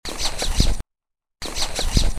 jungle owlet Glaucidium radiatum, Thekkady, Kerala.mp3